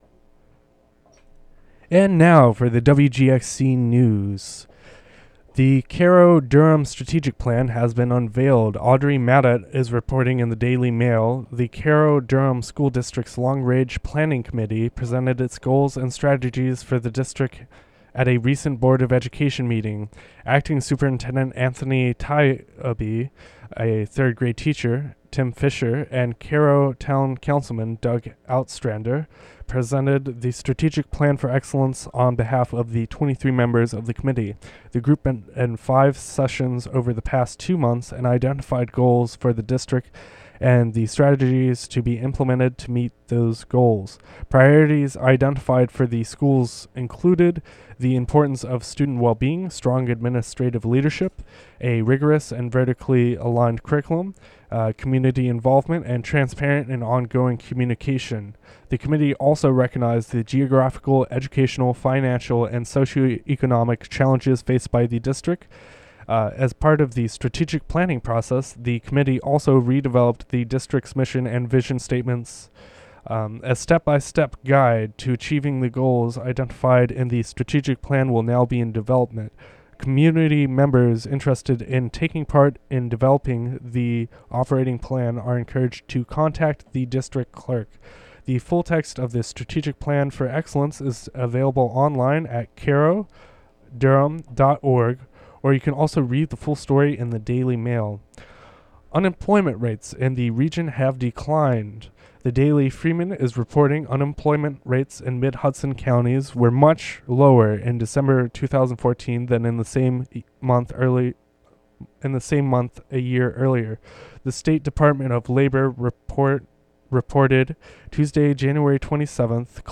Local news and weather for Wednesday, January 28, 2015.